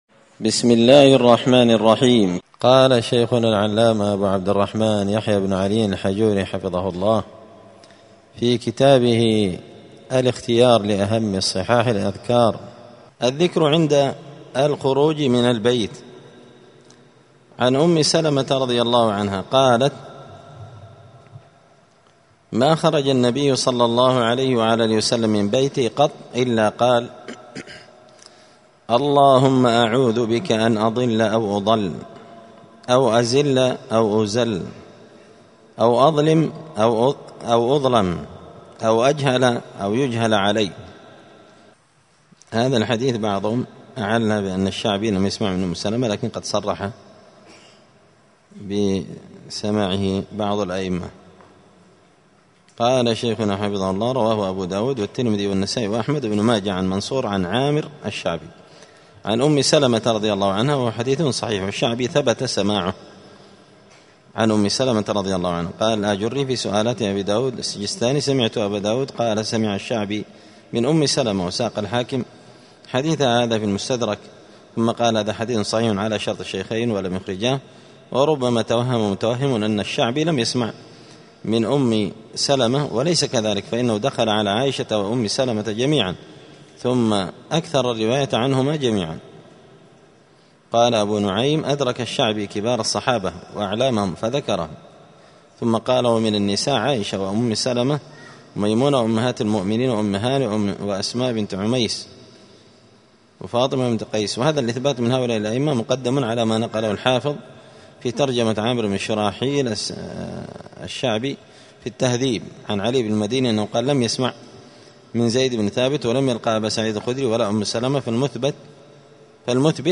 *{الدرس الثامن عشر (18) الذكر عند الخروج من المنزل}*